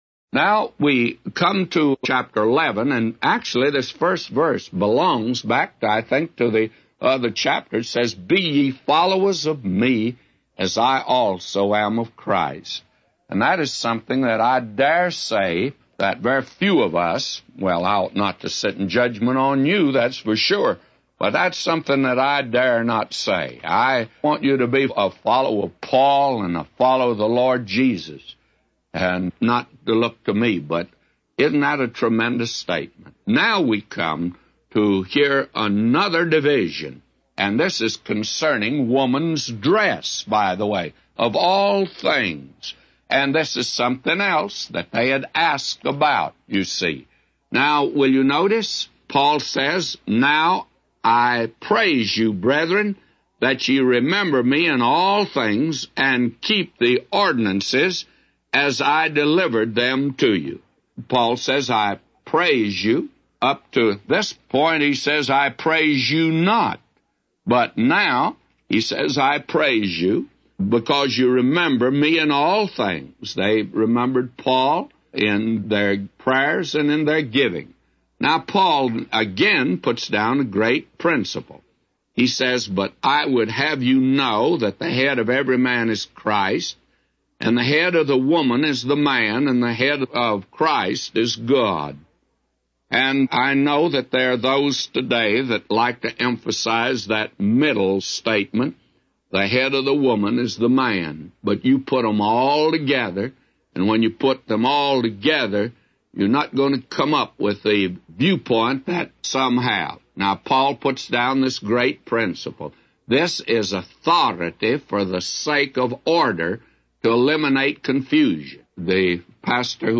A Commentary By J Vernon MCgee For 1 Corinthians 11:1-15